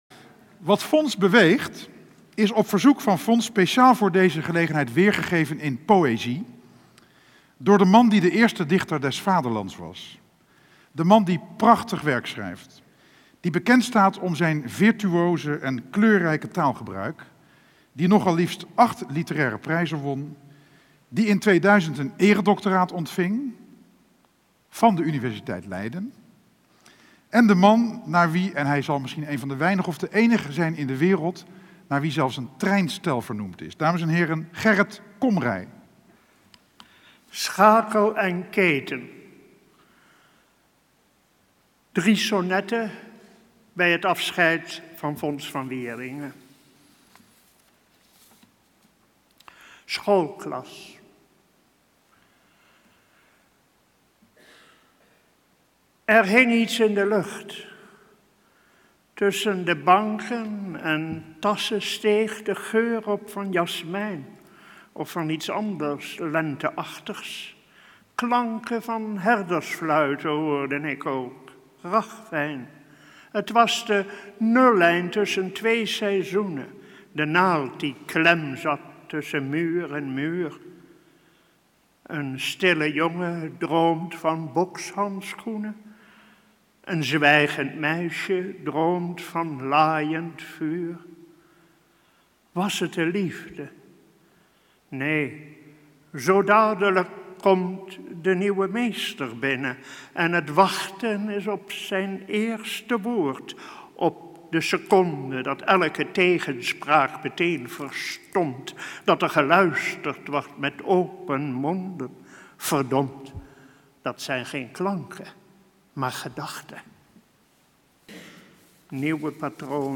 Tijdens een speciale en bijzondere bijeenkomst in de Ridderzaal voerden diverse sprekers het woord. Gerrit Komrij las drie onderwijssonnetten voor. Deze sonnetten waren speciaal ter gelegenheid van deze bijeenkomst geschreven.